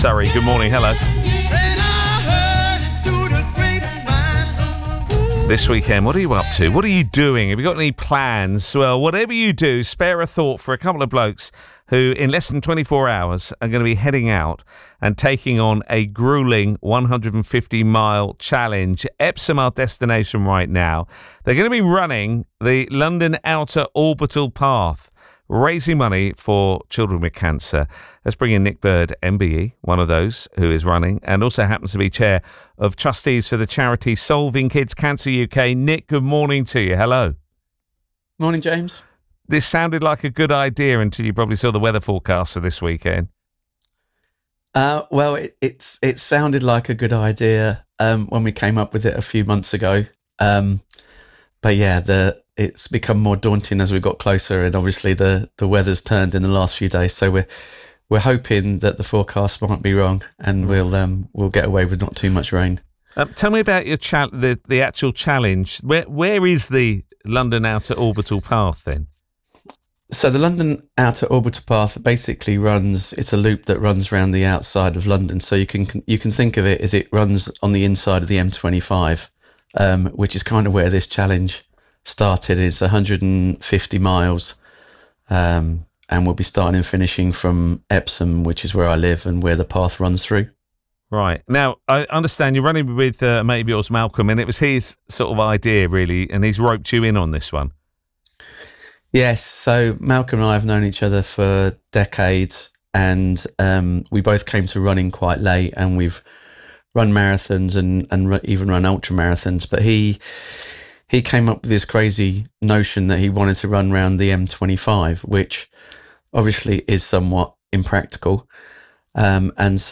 As Heard on BBC Radio Surrey
The interview starts at 3 hours and 39 minutes in.